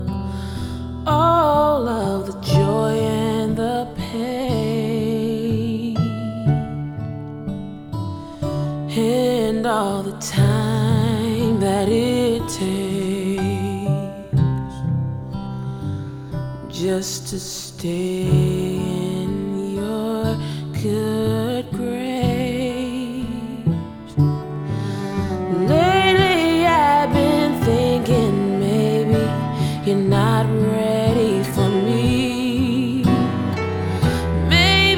Жанр: Поп / R&b / Рок / Соул